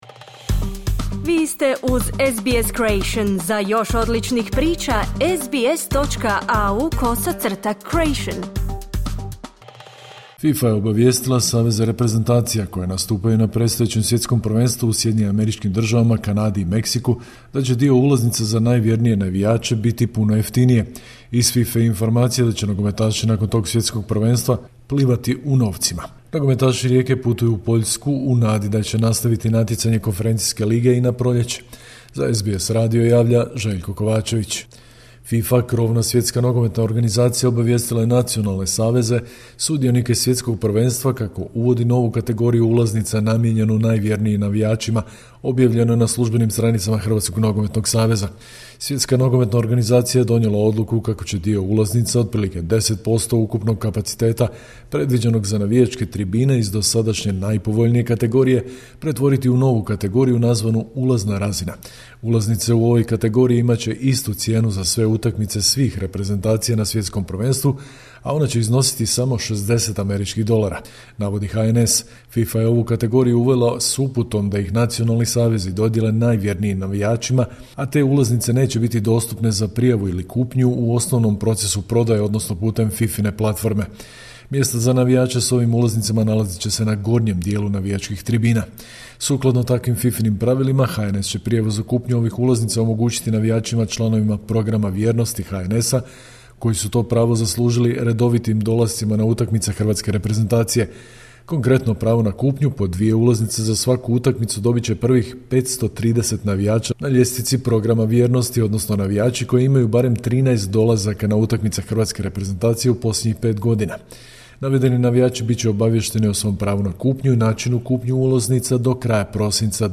Sportske vijesti iz Hrvatske, 18.12.2025.